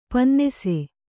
♪ pannese